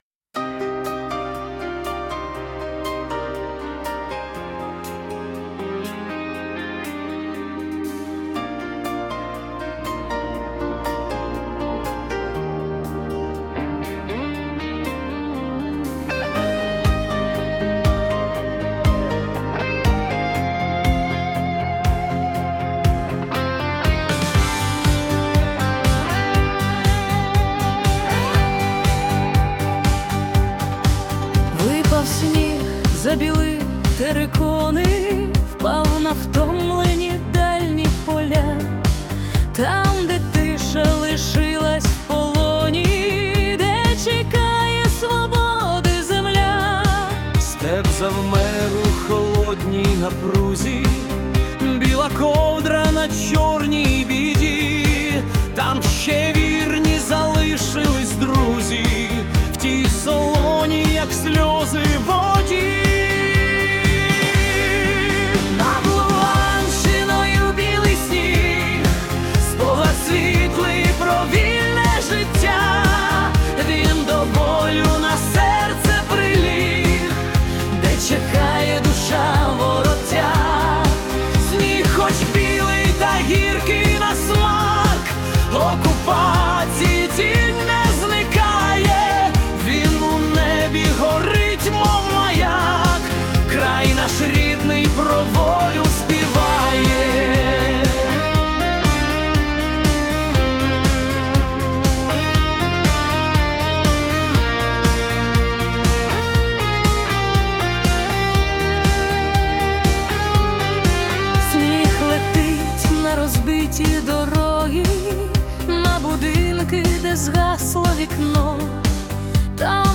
🎵 Жанр: Italo Disco / Синт-вейв
Контраст ритму і болю